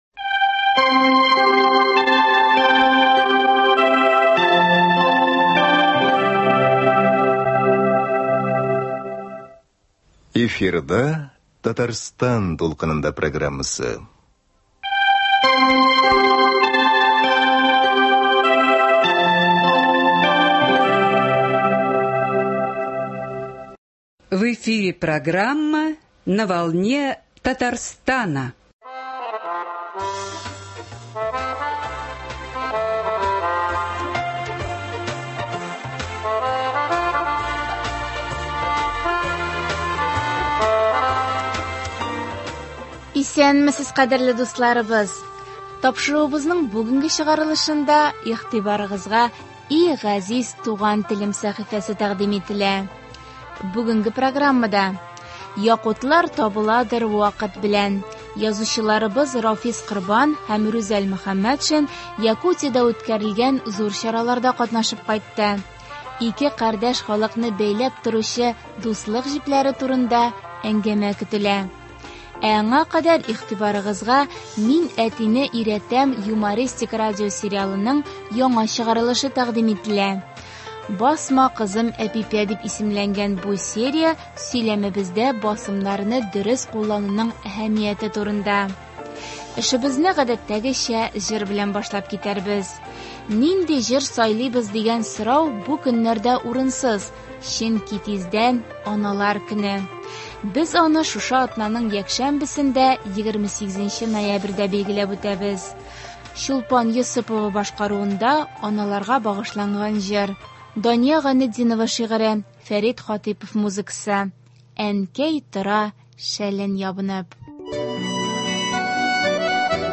Ике кардәш халыкны бәйләп торучы дуслык җепләре турында әңнгәмә көтелә.
Ә аңа кадәр игътибарыгызга “Мин әтине өйрәтәм” юмористик радиосериалның яңа чыгарылышы тәкъдим ителә. “Басма, кызым Әпипә!” дип исемләнгән бу серия сйләмебездә басымнарны дөрес куллануның әһәмияте турында.